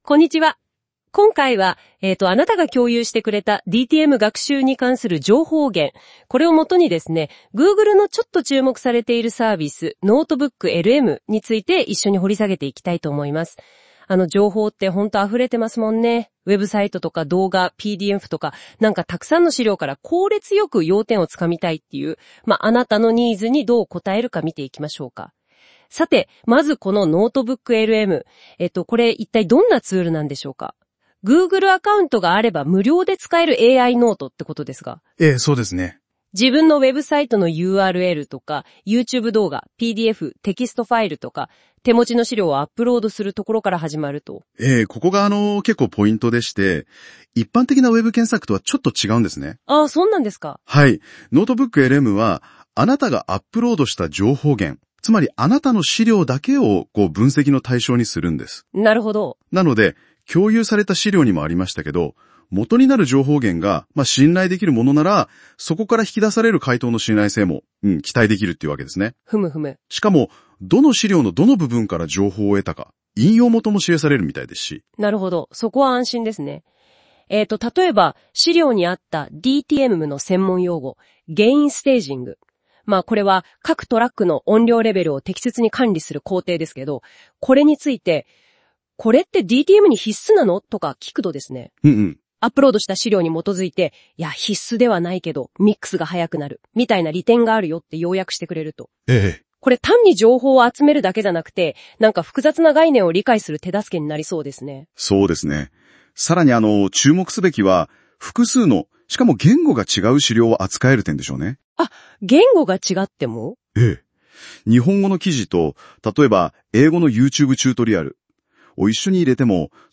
NotebookLMには「音声解説」という、アップロードした資料の内容を二人の会話形式で音声化してくれる注目すべき機能があります。
▼音声解説 サンプル
生成された音声とは思えないほど自然で聞き取りやすい発音です。